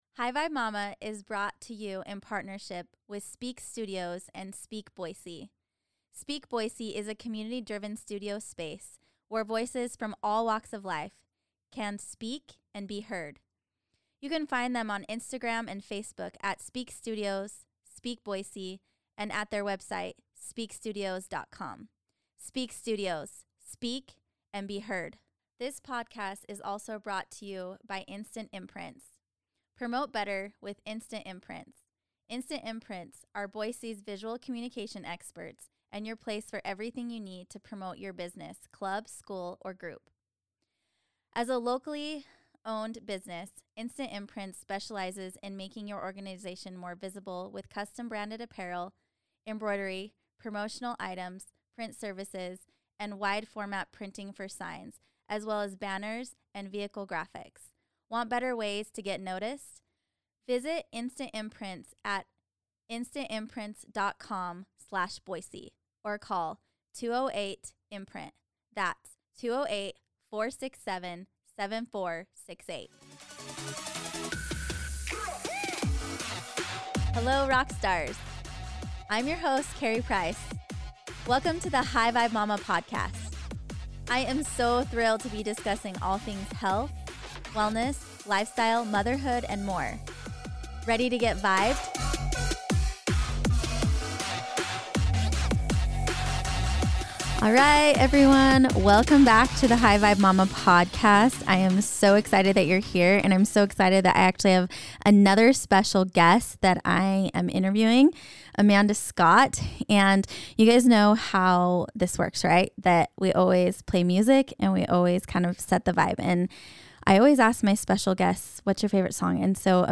#9 LIVING A NON-TOXIC LIFESTYLE, INTERVIEW W